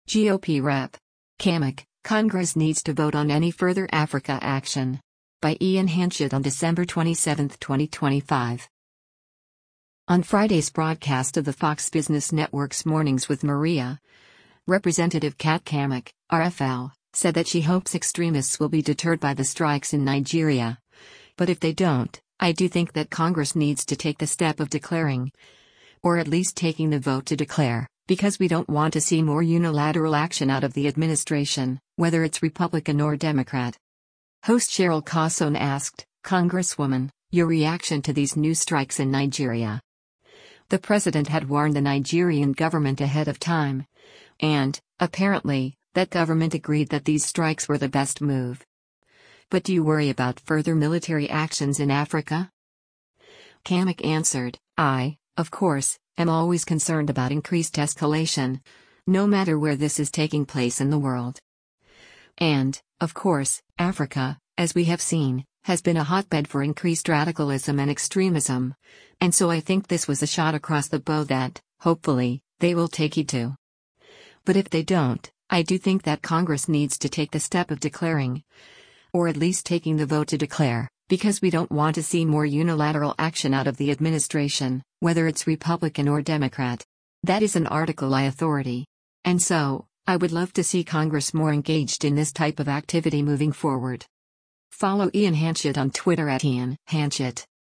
On Friday’s broadcast of the Fox Business Network’s “Mornings with Maria,” Rep. Kat Cammack (R-FL) said that she hopes extremists will be deterred by the strikes in Nigeria, “But if they don’t, I do think that Congress needs to take the step of declaring, or at least taking the vote to declare, because we don’t want to see more unilateral action out of the administration, whether it’s Republican or Democrat.”